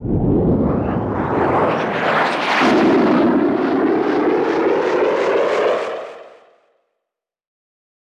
jet2.wav